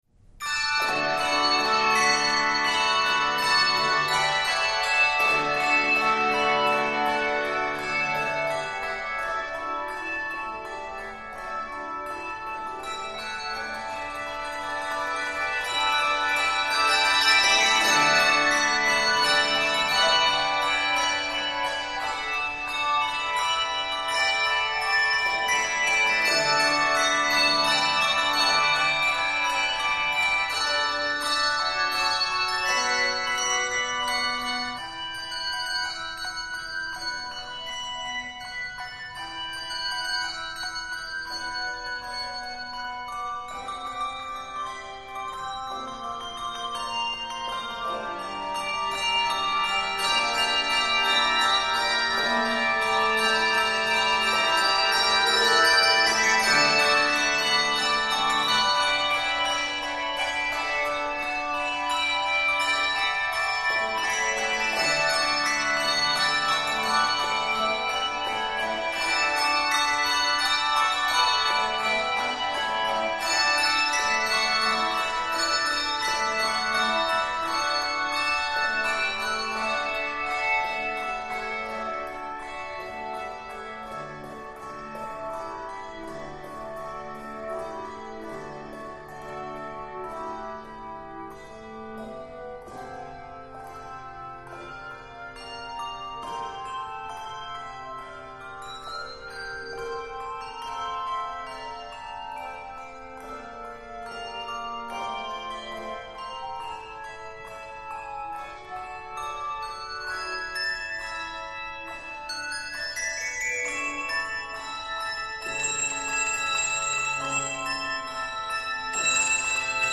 Voicing: Handbells 4-5 Octave